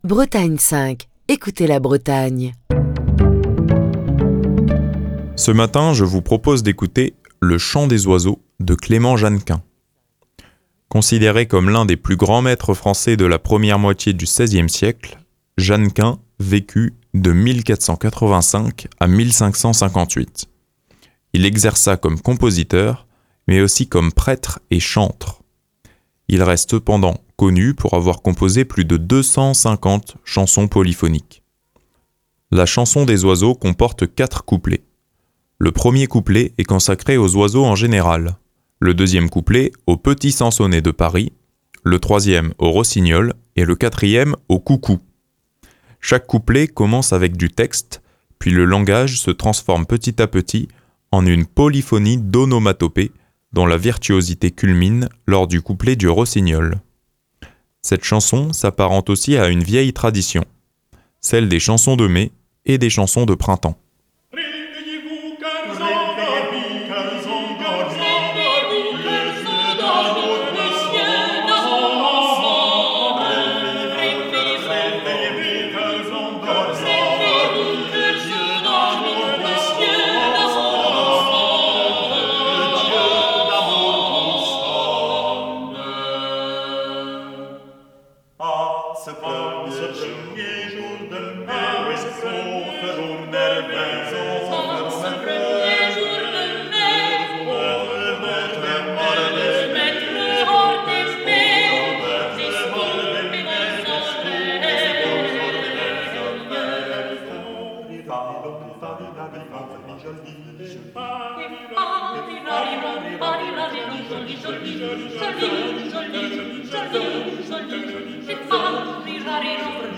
La chanson des oiseaux comporte 4 couplets : le 1er couplet est consacré aux oiseaux en général, le 2ème couplet au petit sansonnet de Paris, le 3ème au rossignol et le 4ème au coucou. Chaque couplet commence avec du texte, puis le langage se transforme petit à petit en en une polyphonie d’onomatopées dont la virtuosité culmine lors du couplet du rossignol.